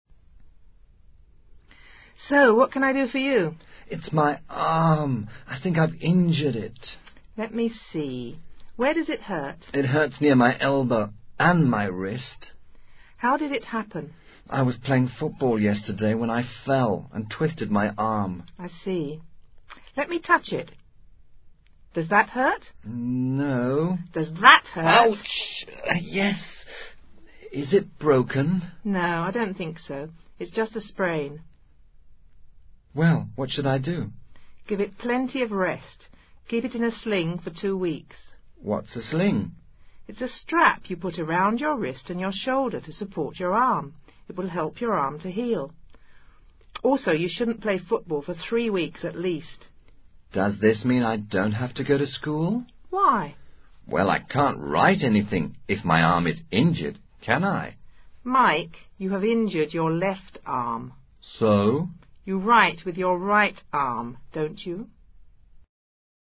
Diálogo entre un médico y un joven, quien se ha torcido un brazo.